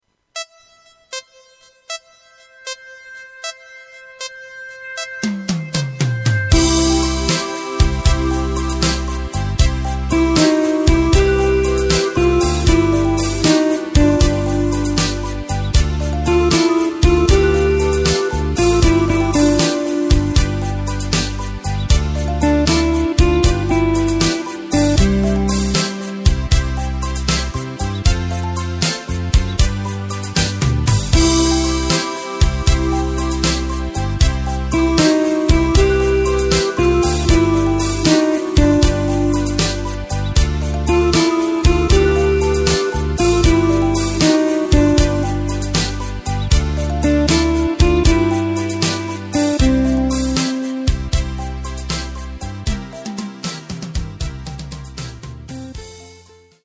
Takt:          4/4
Tempo:         78.00
Tonart:            C
Austropop aus dem Jahr 2016!